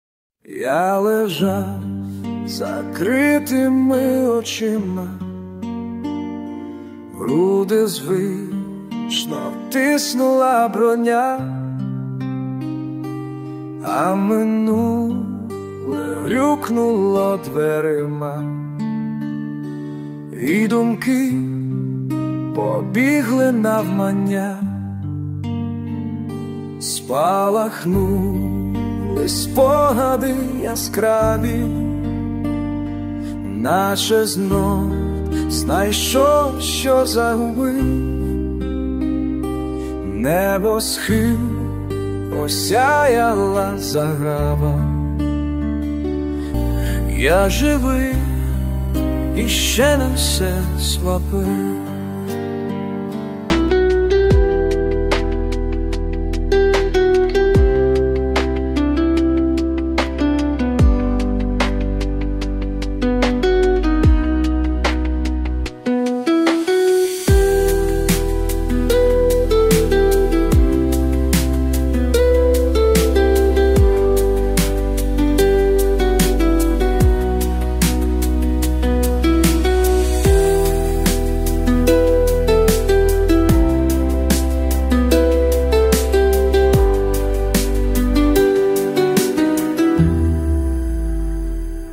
Текст - автора, мелодія і виконання - ШІ
ТИП: Пісня
СТИЛЬОВІ ЖАНРИ: Ліричний